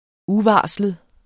Udtale [ ˈuˌvɑːsləð ]